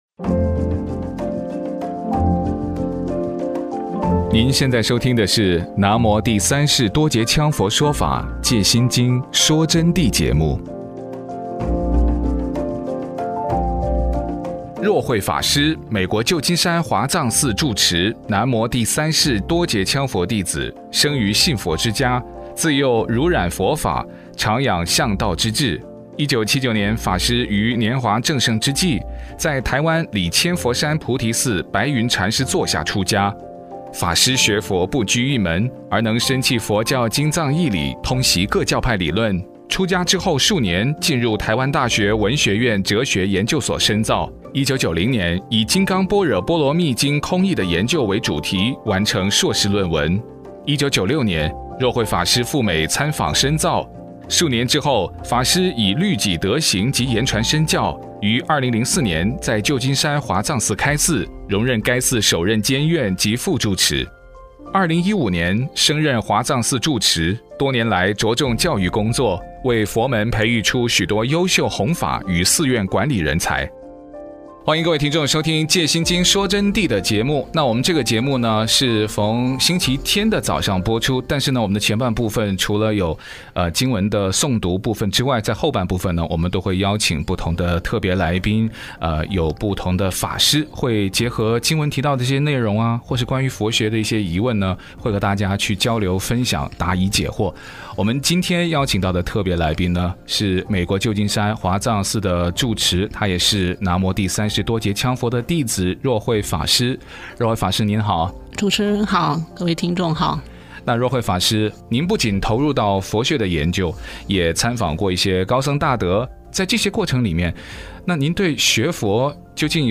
KAZN AM1300 中文广播电台每周日早上九点播出